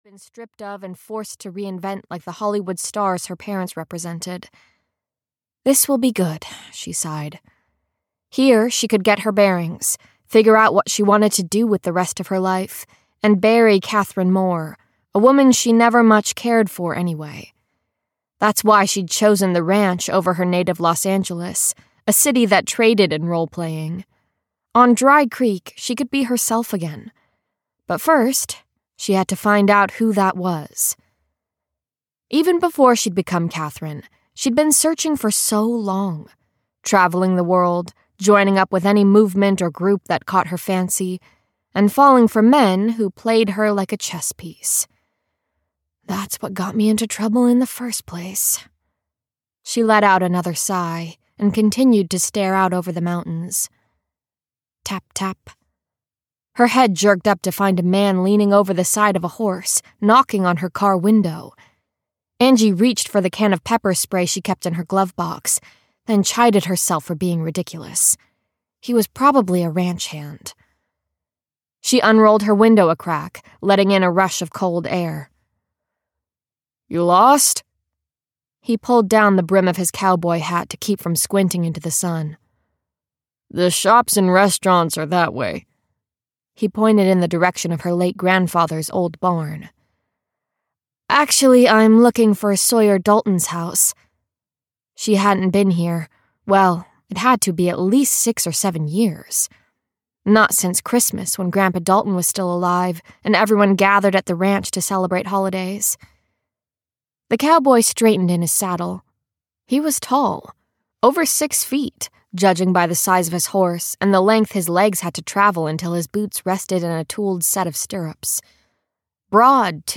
Cowboy Proud (EN) audiokniha
Ukázka z knihy
cowboy-proud-en-audiokniha